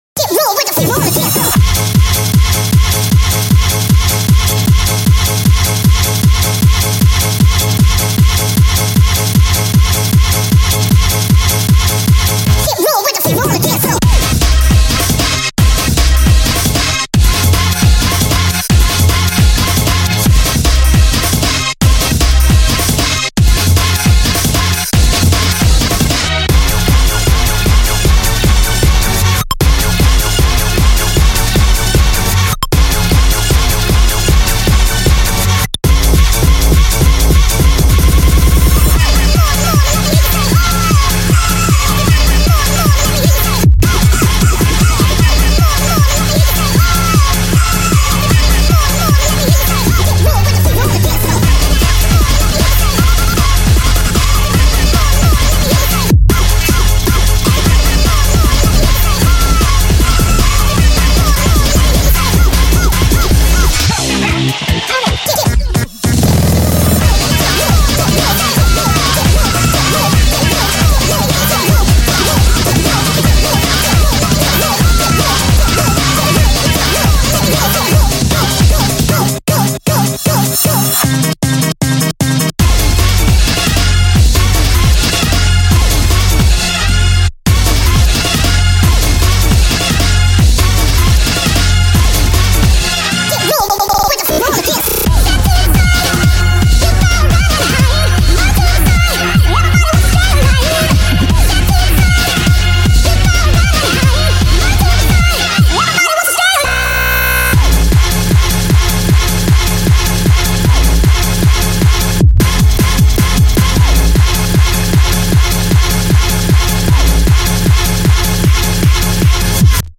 BPM154
Audio QualityPerfect (High Quality)